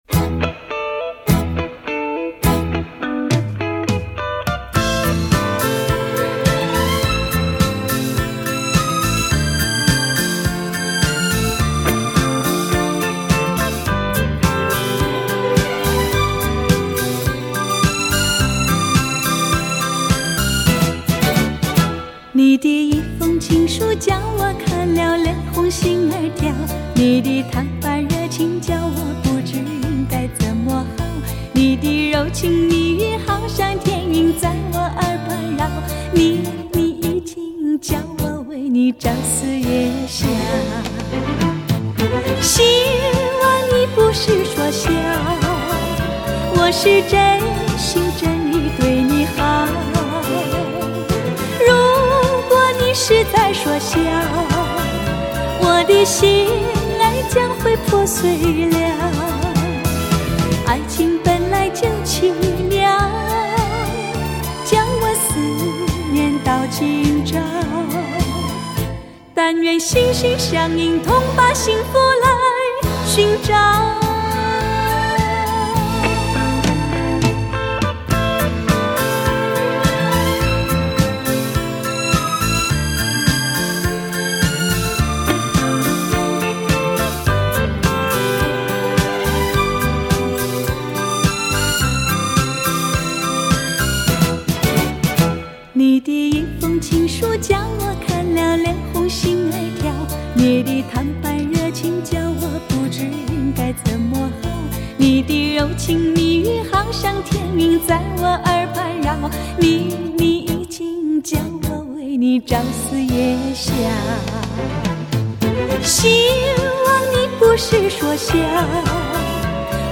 DXD重新编制